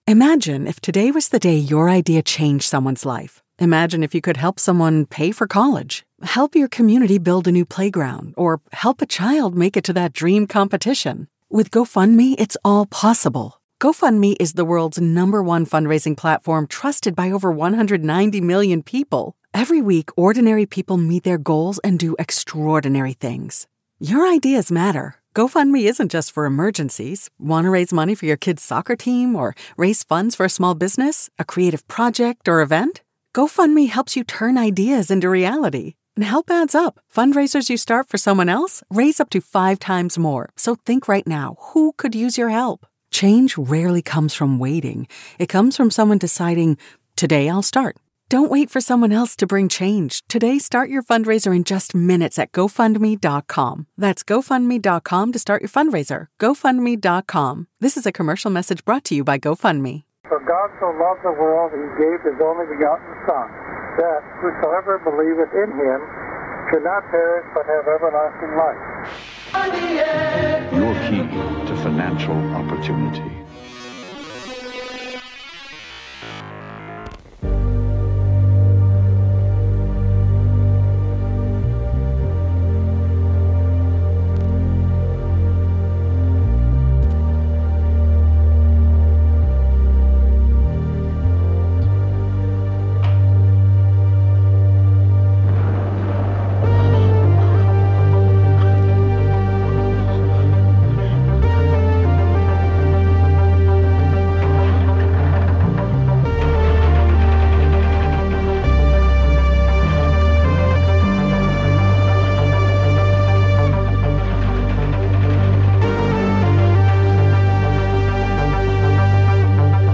True Crime Narratives